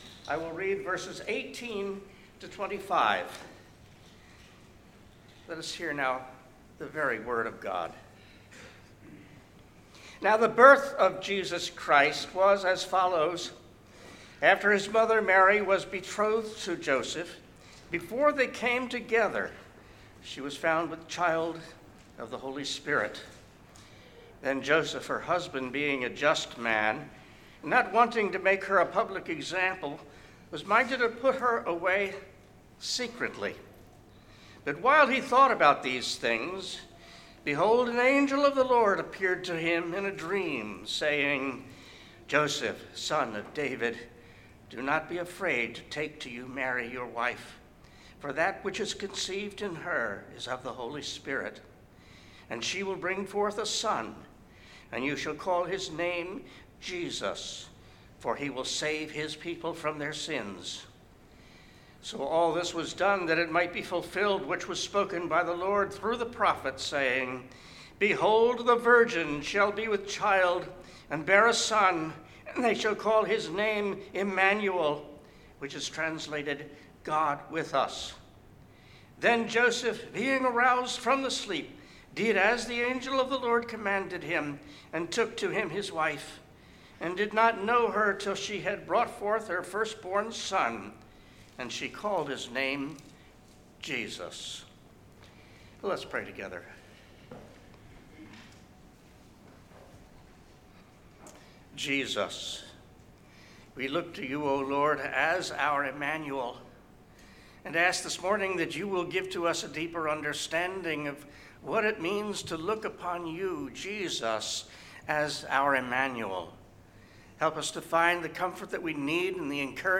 Sermon: What Does Immanuel Mean to You?
Passage: Matthew 1:18-25 Service Type: Worship Service « Advent 2025